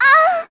Ophelia-Vox_Damage_02_kr.wav